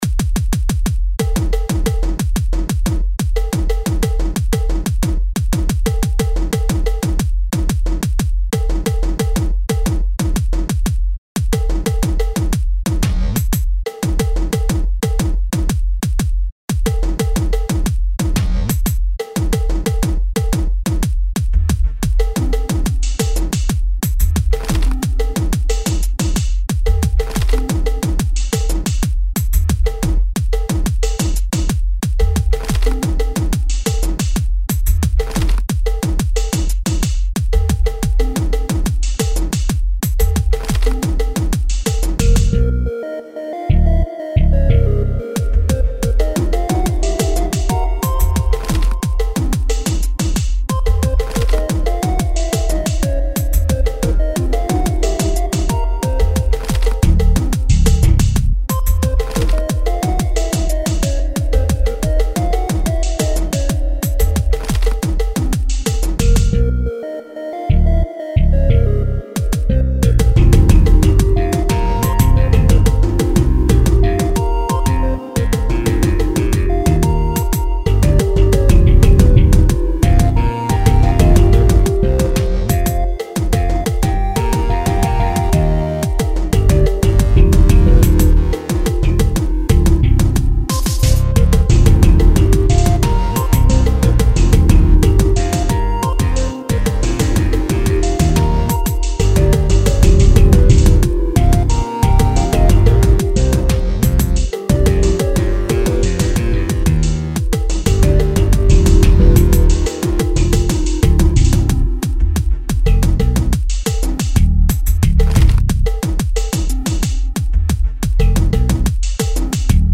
Categorised in: rhythmic